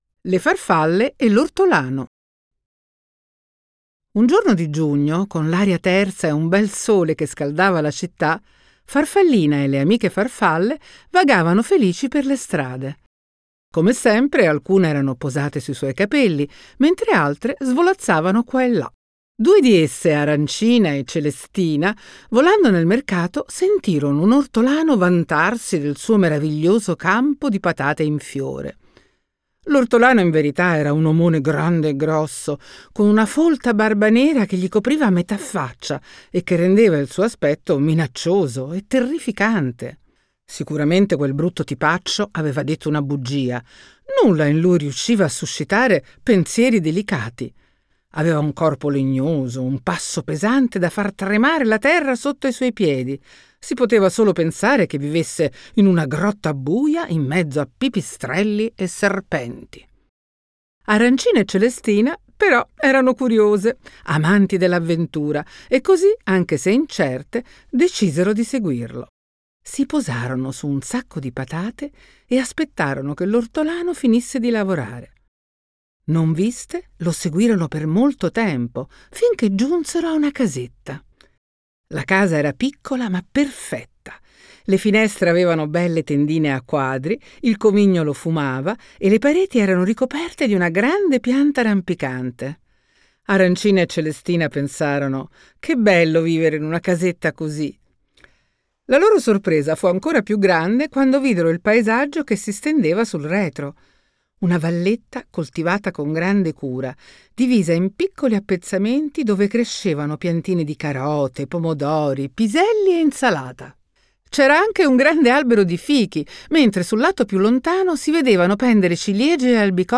Voce di Serena Dandini